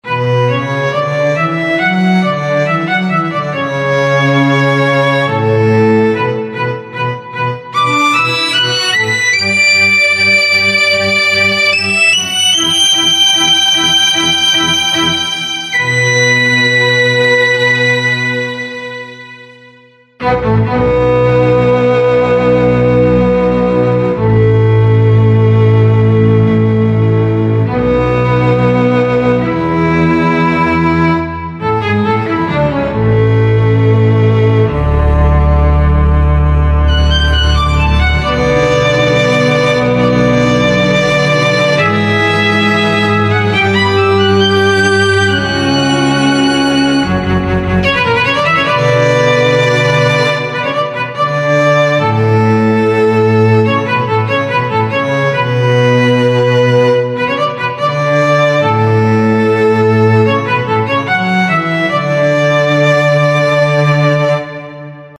HALion6 : Studio Strings